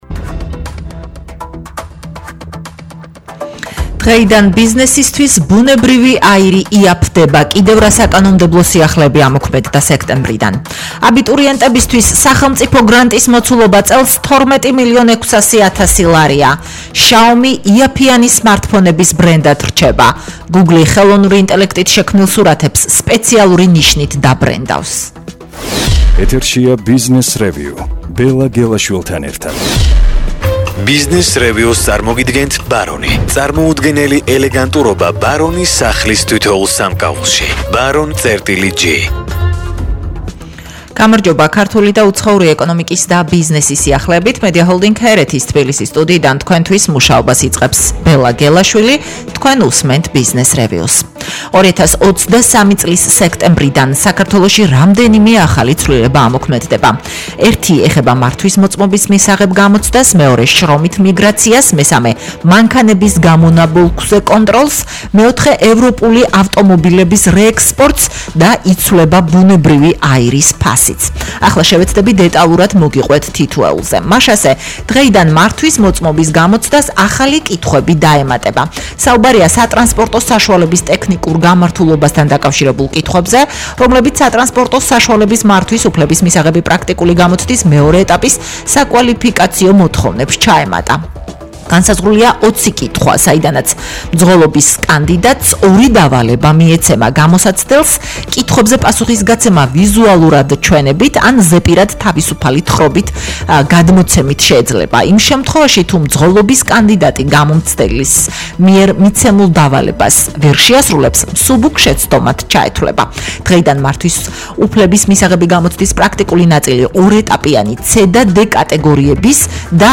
„ბიზნესრევიუ” [გადაცემა] – 01.09.2023.